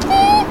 クーコールが聞けます。
大平山1歳のクーコール